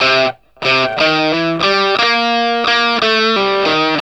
WALK1 60 C.A.wav